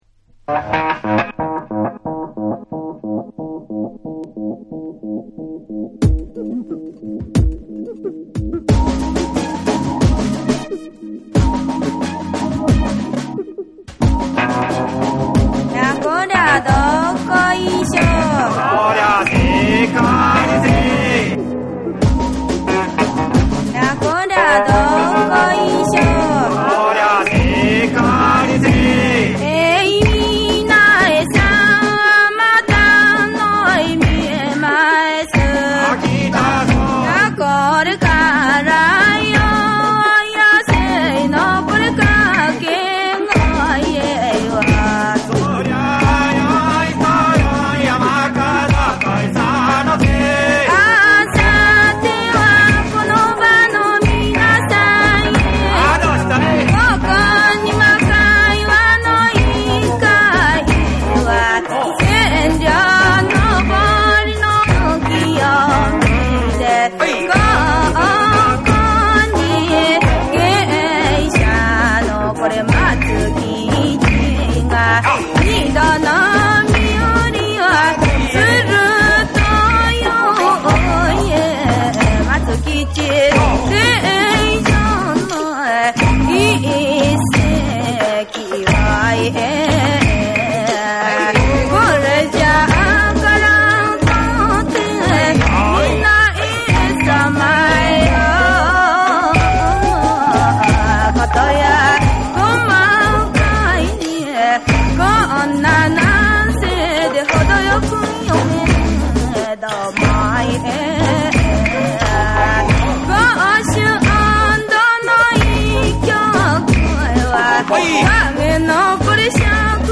ダンスミュージックのエッセンスとビートが加えられたリミックス3種の無国籍感も大胆で素晴らしい仕上がり。
JAPANESE / WORLD